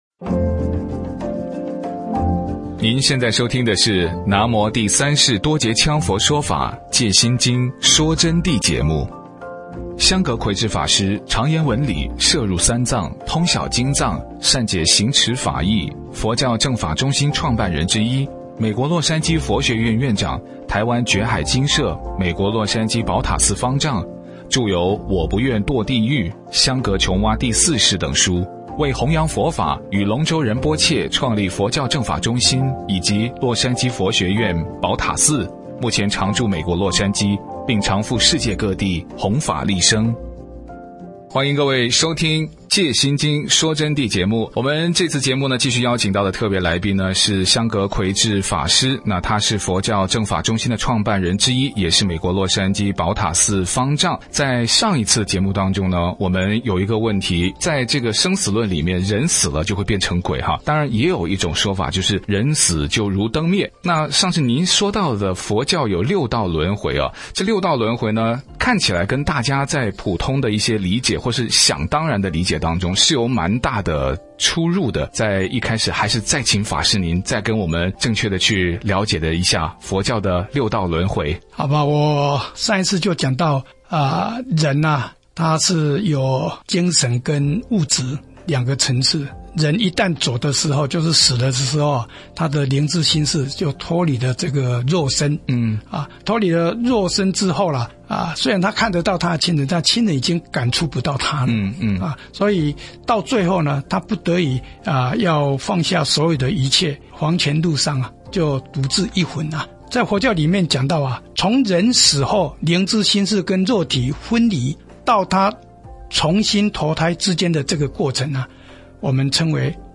佛弟子访谈（四）六道轮回是怎么回事？与因果报应有什么关系？